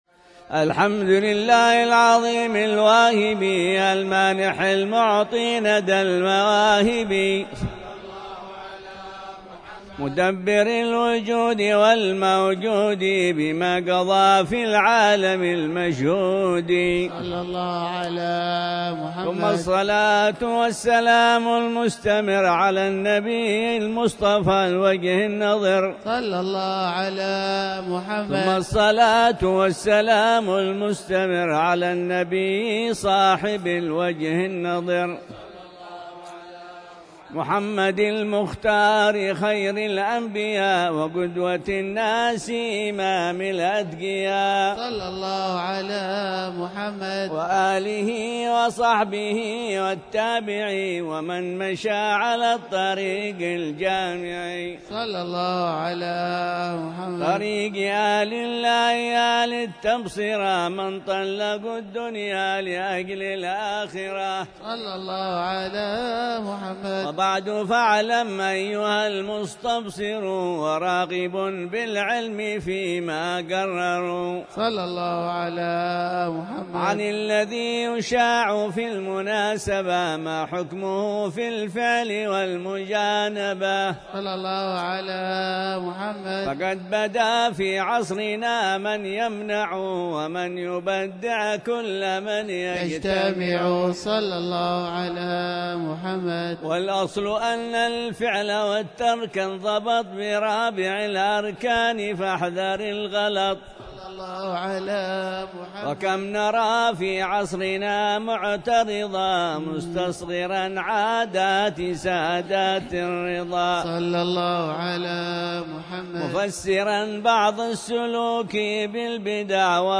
مجلس قراءة منظومة العشر من ذي الحجة